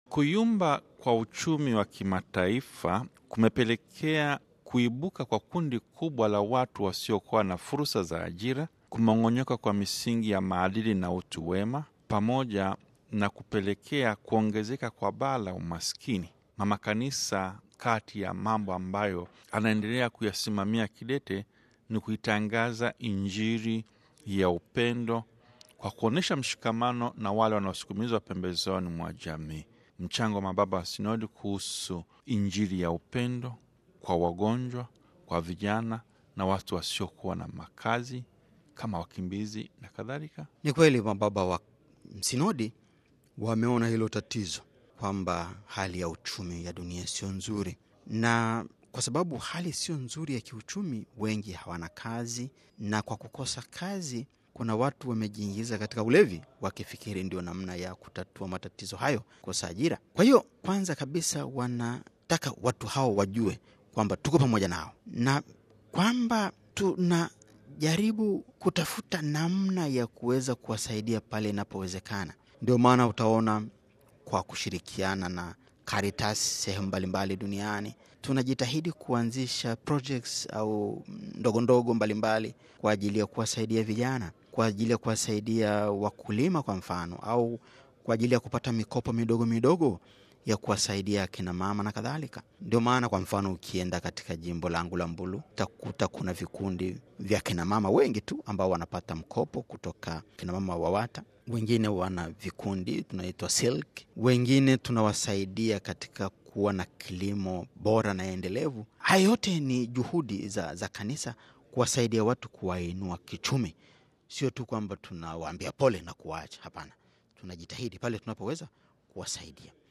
Askofu Beatus Kinyaiya wa Jimbo Katoliki Mbulu, katika mahojiano na Radio Vatican anabainisha kwamba, Kanisa limeendelea kuonesha mshikamano wake wa dhati na maskini pamoja na wote wanaosukumizwa pembezoni mwa Jamii kwa kuwatangazia Injili ya Upendo, inayopania kuwajengea uweze wa kupambana na hali ngumu ya maisha kwa kuwekeza katika miradi ya elimu, uchumi na kijamii, dhamana inayotekelezwa kwa namna ya pekee na Shirika la Misaada la Kanisa Katoliki, Caritas katika ngazi mbali mbali.